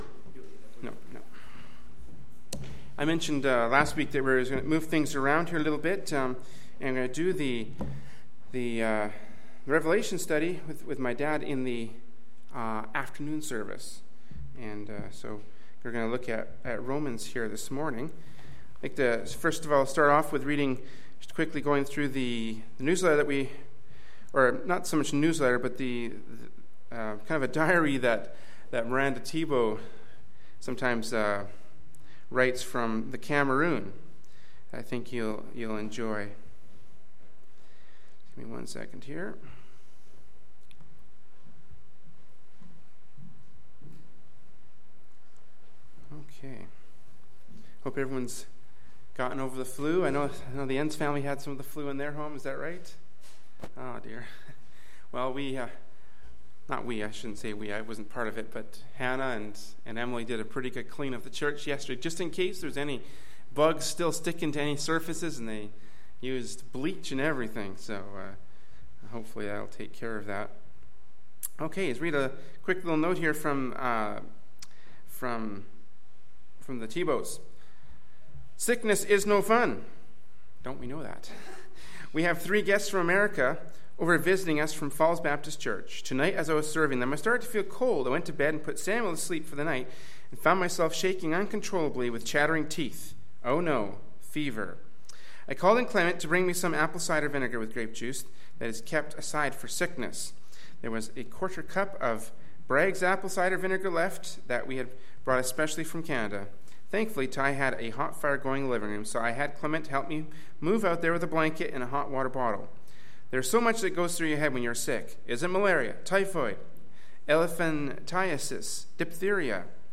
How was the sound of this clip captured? Kamloops, B.C. Canada